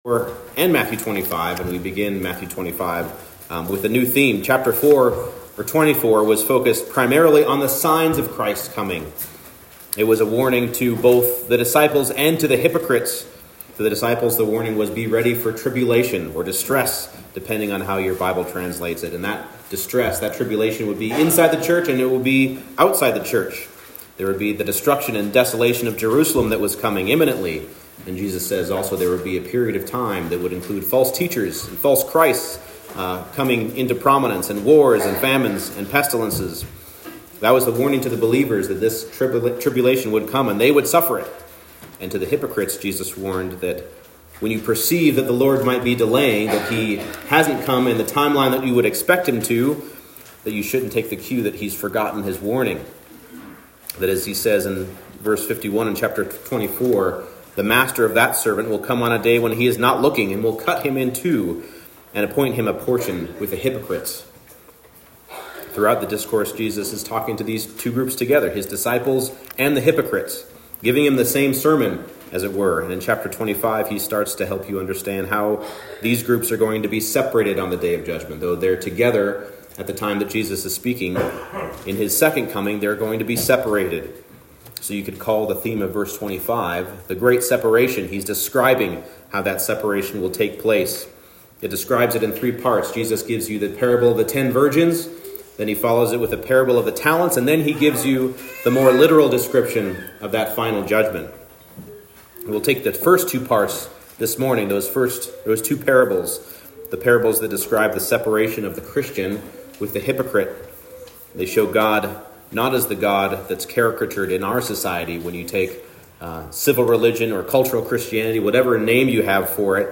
Matthew 25:1-30 Service Type: Morning Service Be prepared with active waiting for your Lord to return.